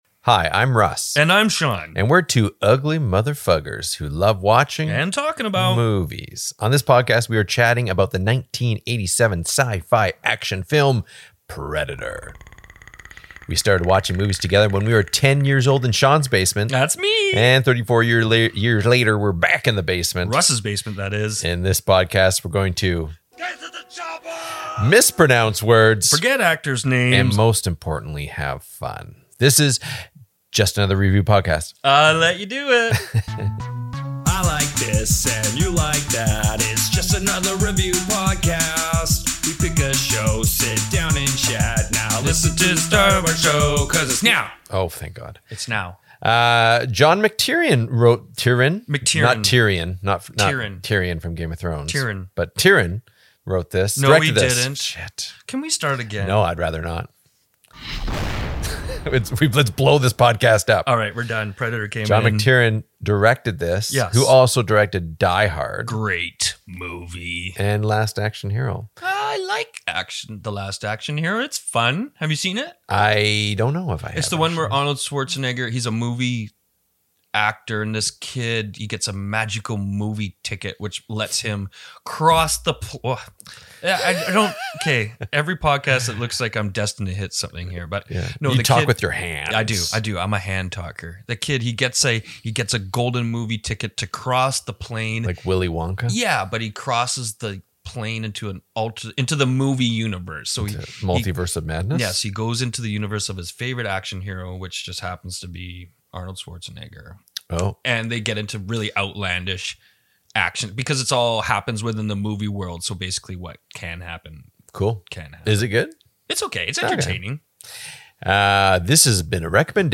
Technical issues abound!